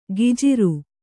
♪ gijiru